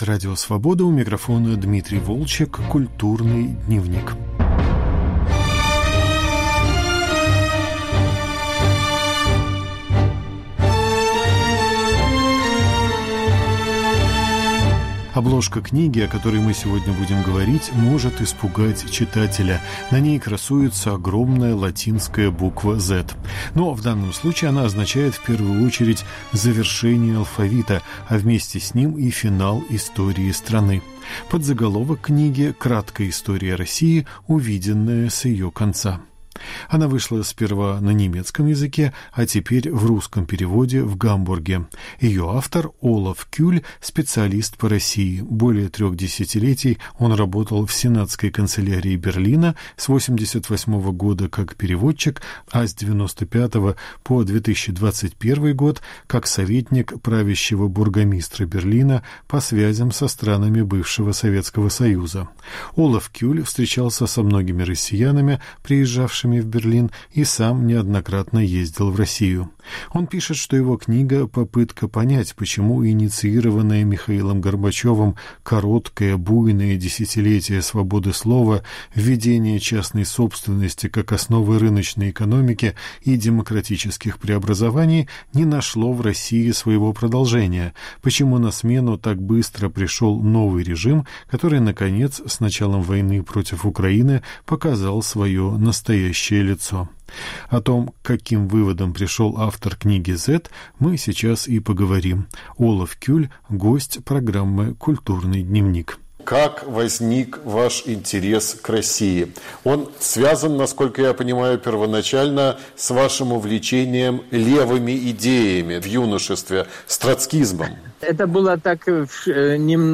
Разговор о книге “Z”.